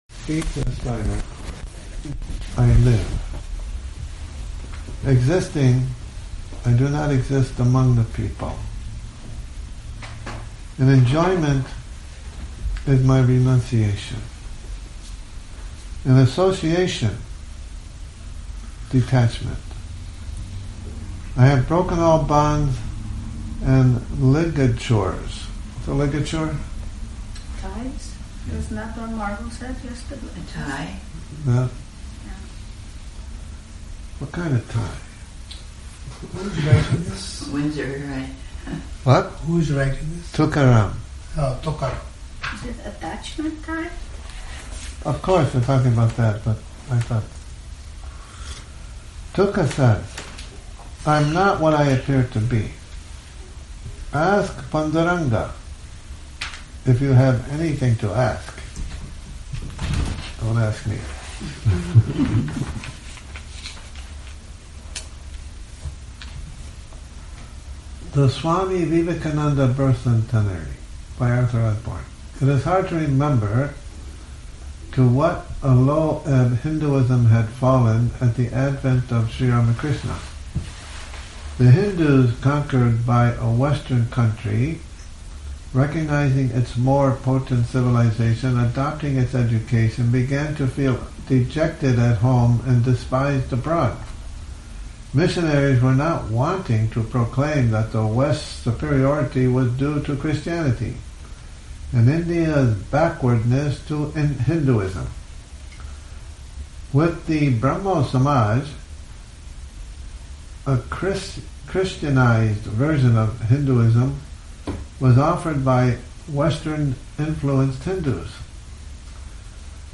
Morning Reading, 08 Oct 2019